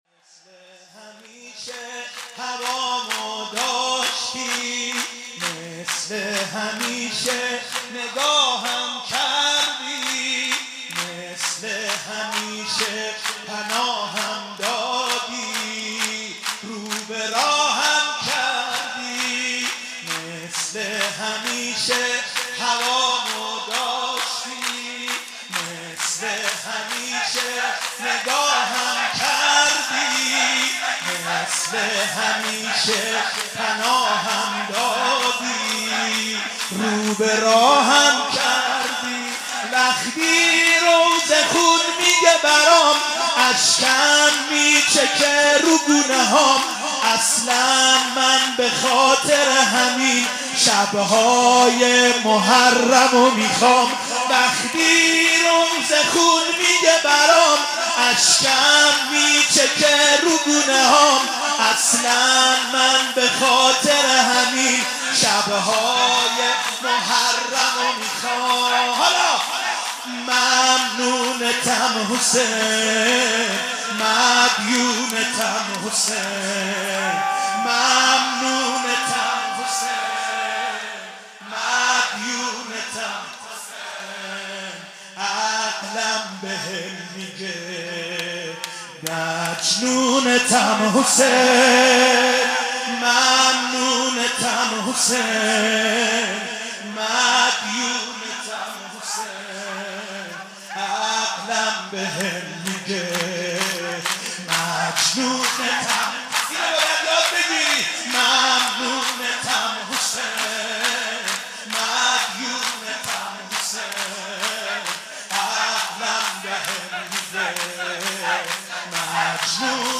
مراسم شب هشتم محرم الحرام سال 1395